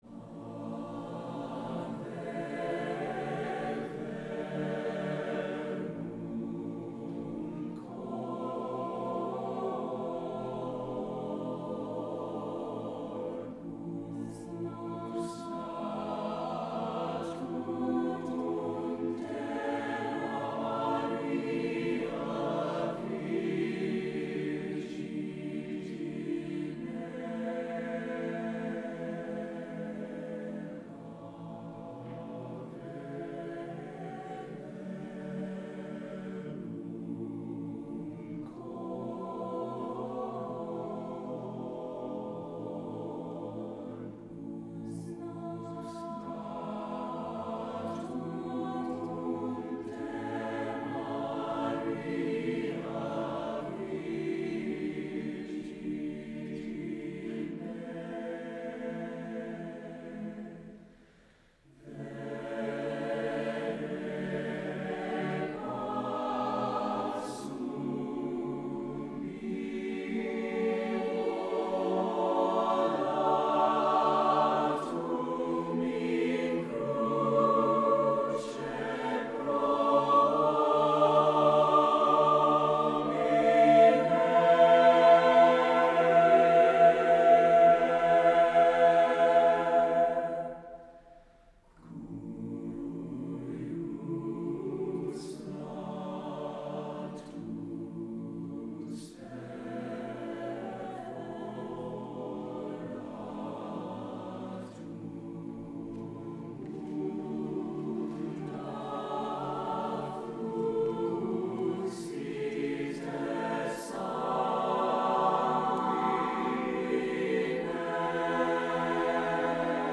Ave Verum Corpus – motet SATB divisi